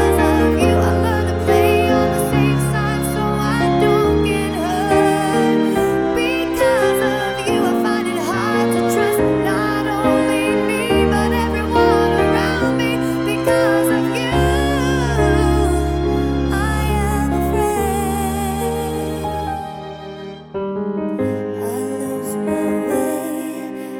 Down 2 Semitones